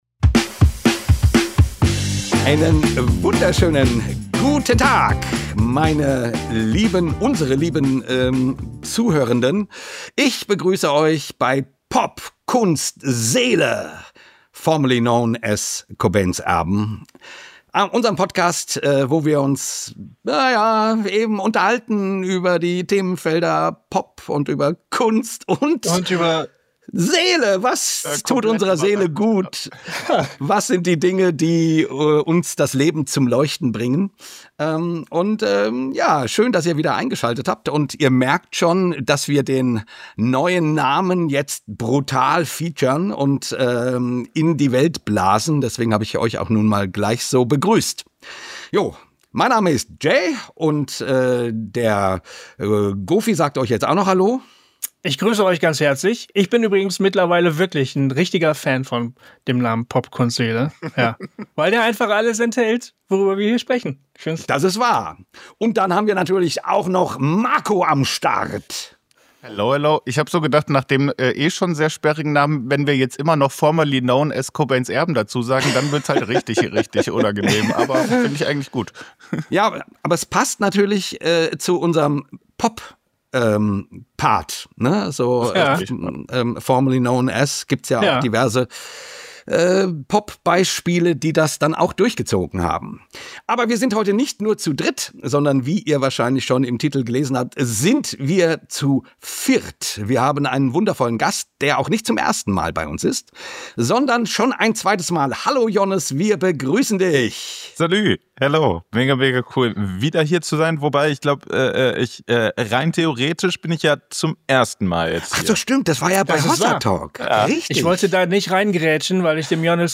Ein sehr persönlicher und unterhaltsamer Talk unter Freunden.